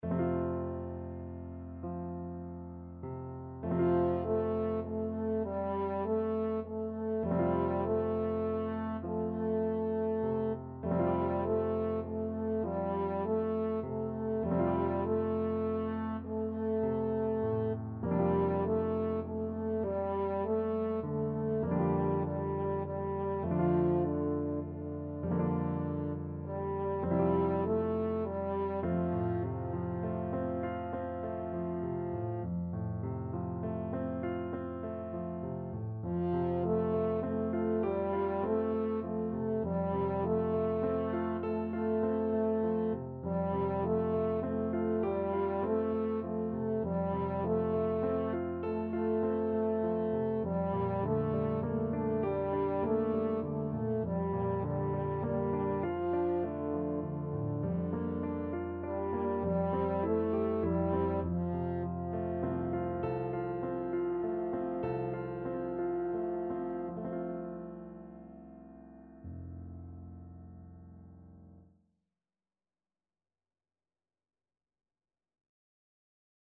French Horn version
3/4 (View more 3/4 Music)
Gently =c.100
Classical (View more Classical French Horn Music)